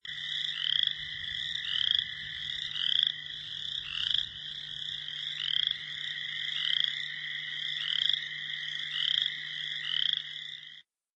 spring-peepers-frogs.mp3